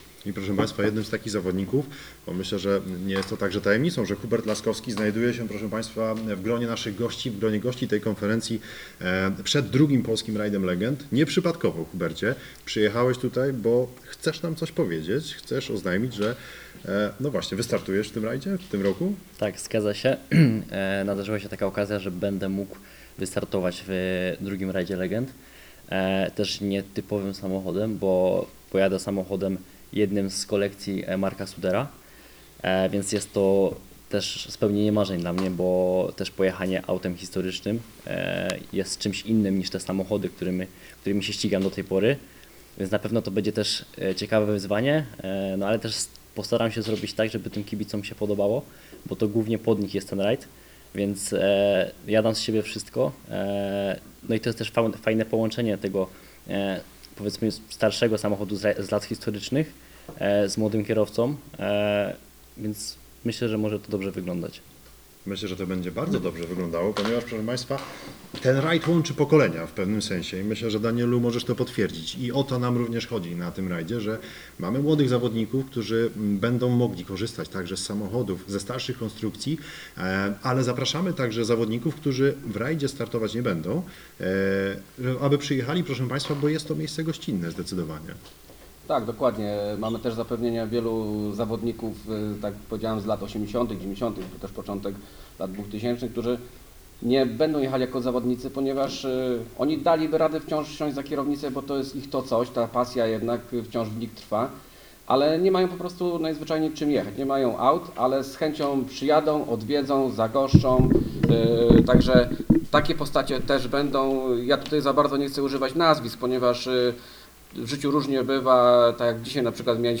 16 czerwca br. w hotelu Polanica Resort SPA odbyła się konferencja prasowa dotycząca 2. Polskiego Rajdu Legend, który rozgrywany będzie 29 i 30 sierpnia na terenie sześciu gmin powiatu kłodzkiego.